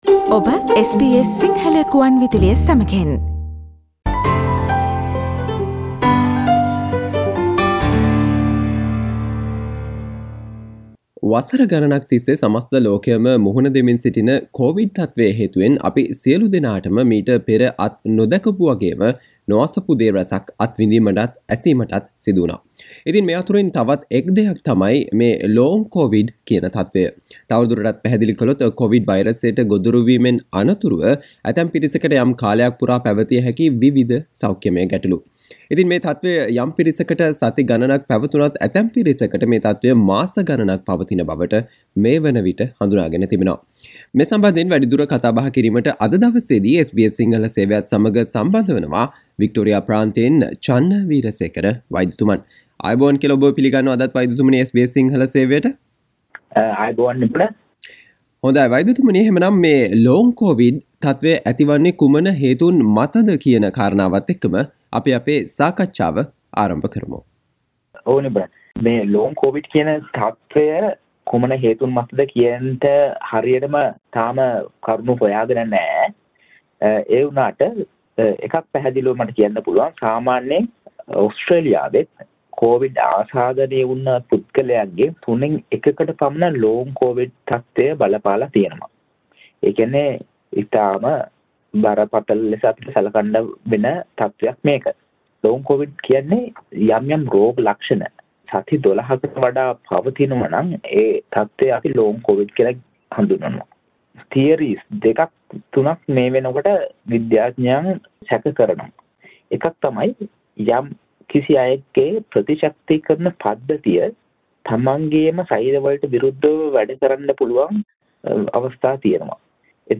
කොවිඩ් වෛරසයට ගොදුරු වීමෙන් අනතුරුව ඇතැම් පිරිසකට මුහුණ දීමට සිදුවන යම් සෞඛ්‍යමය ගැටළුකාරී තත්වයක් වන Long COVID තත්වය පිළිබඳව SBS සිංහල සේවය සිදු කල සාකච්චාවට සවන්දෙන්න